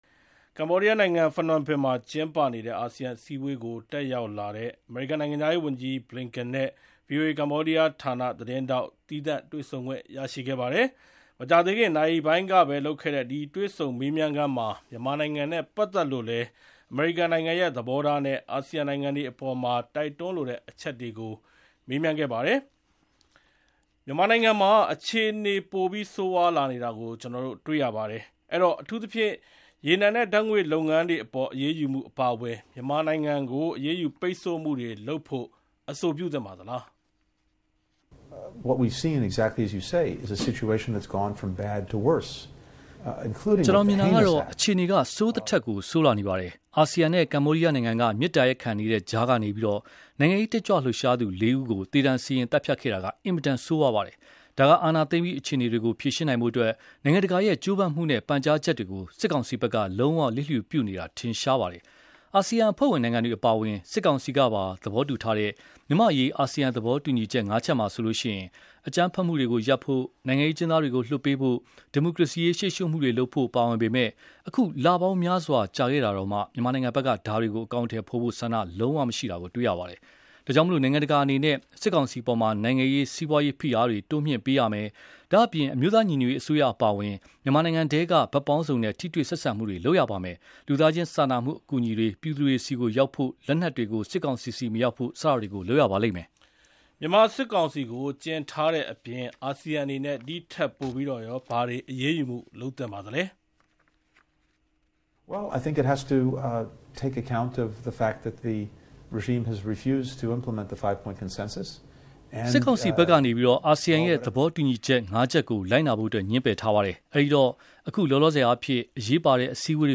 ကန်နိုင်ငံခြားရေးဝန်ကြီးနဲ့ မြန်မာ့အရေး VOA မေးမြန်းမှု